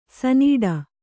♪ sahīḍa